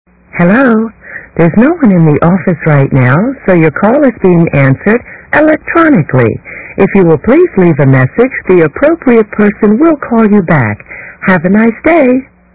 Female Employee
female-employee.mp3